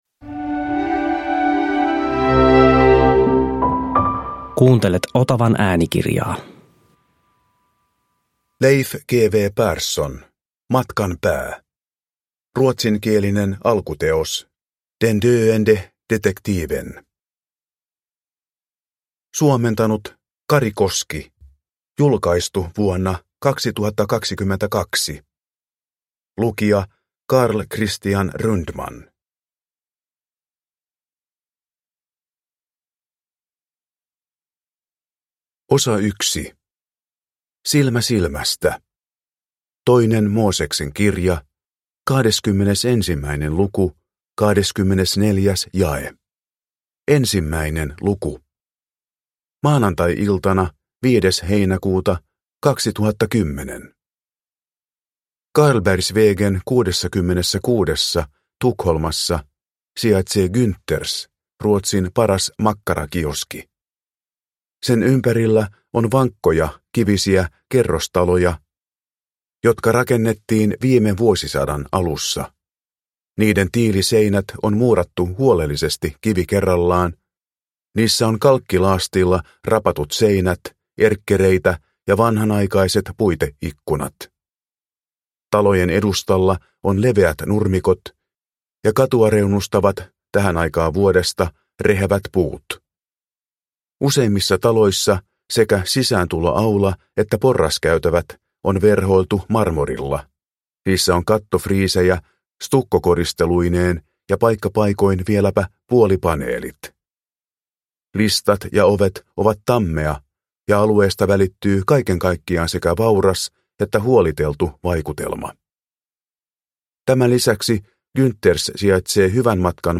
Matkan pää – Ljudbok – Laddas ner